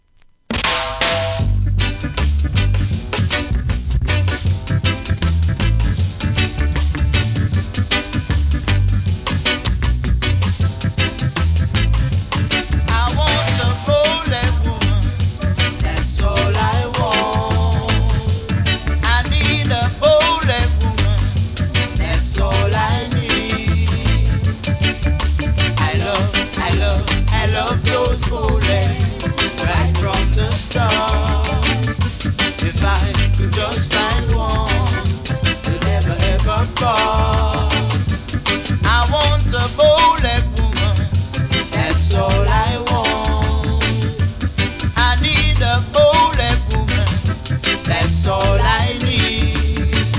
¥ 880 税込 関連カテゴリ REGGAE 店舗 ただいま品切れ中です お気に入りに追加 1968年名作!!